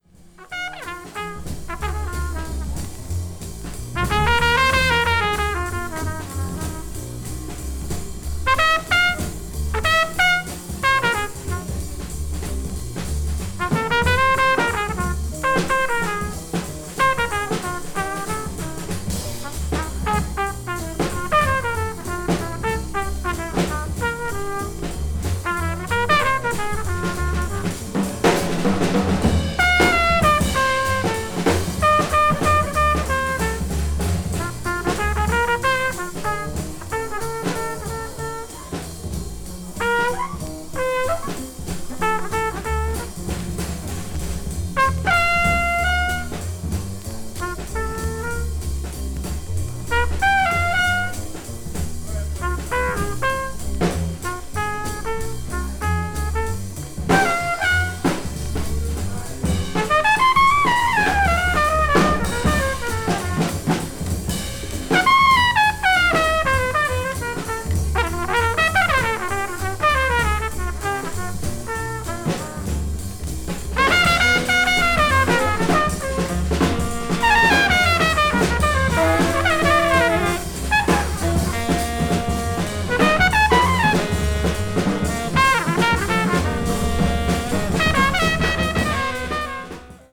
new forms of jazz
oriental scale phrasing
a groovy tune